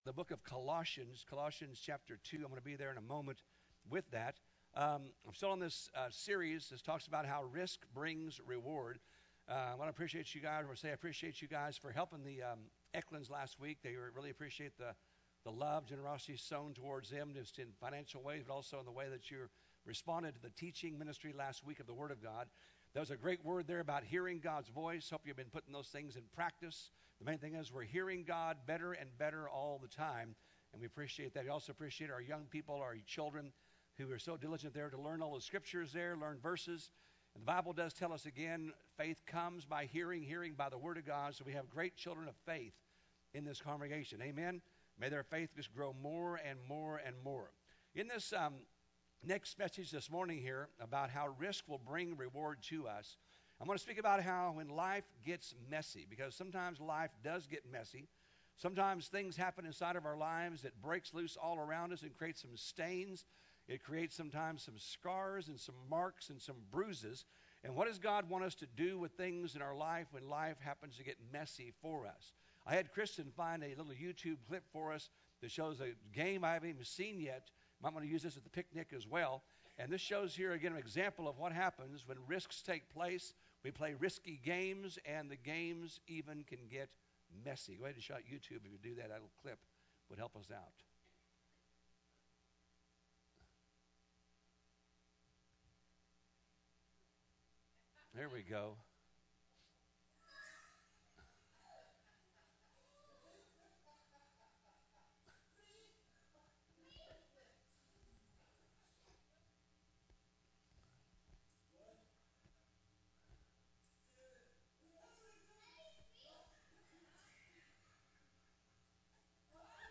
Sermons Archive - Page 23 of 40 - Tree Of Life Church